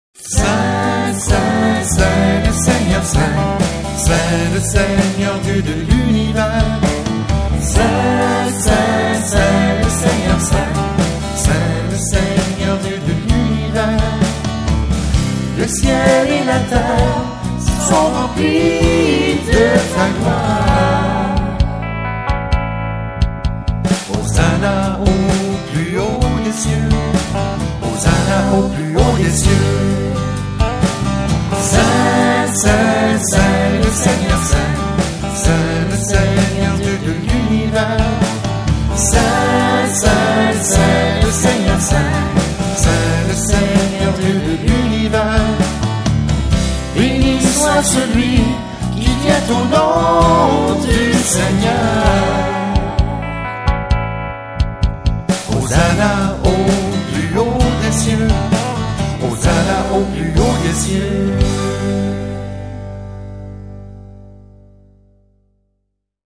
Chants divers
sanctus_chant.mp3